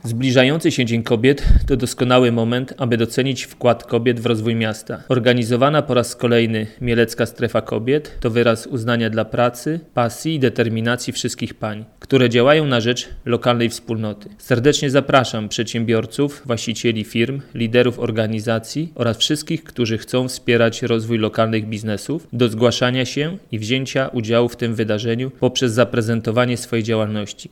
Mówi prezydent Mielca Jacek Wiśniewski.